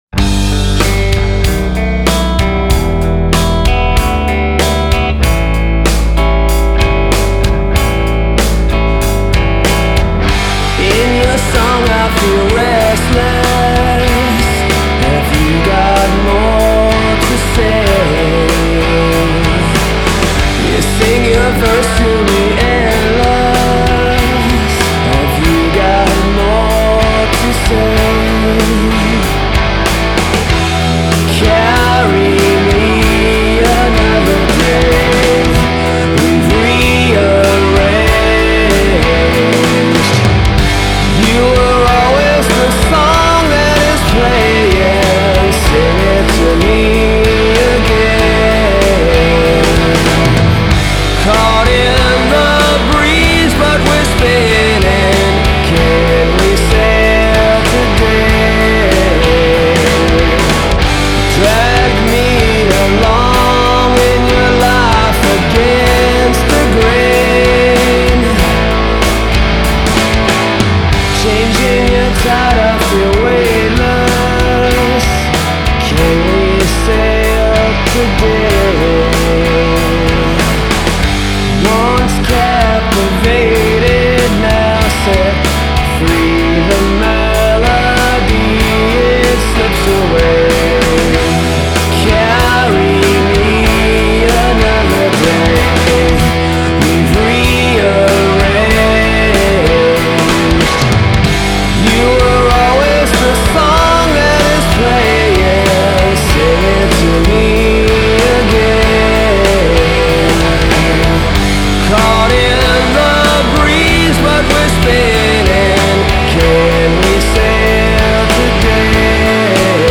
Alternative Rock (Full Band)